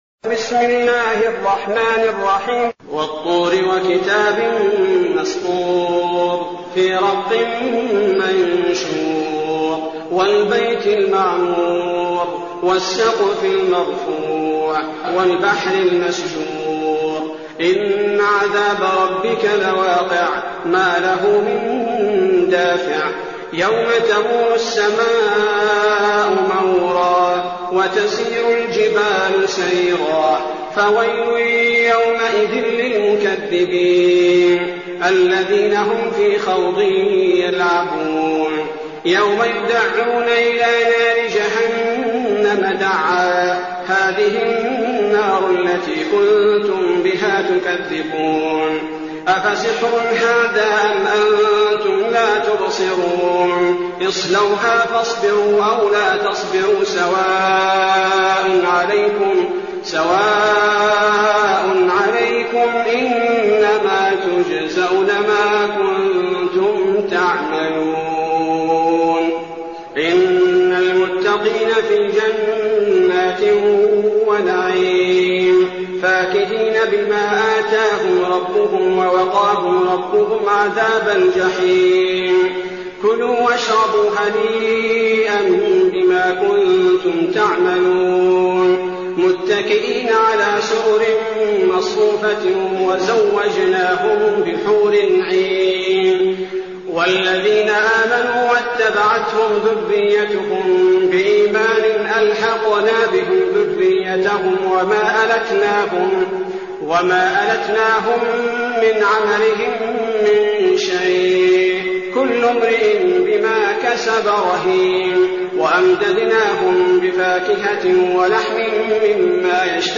المكان: المسجد النبوي الشيخ: فضيلة الشيخ عبدالباري الثبيتي فضيلة الشيخ عبدالباري الثبيتي الطور